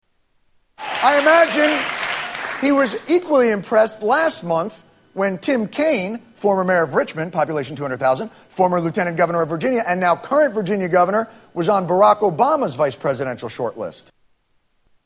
Sarcastic about Karl Rove's hypocrisy
Category: Comedians   Right: Personal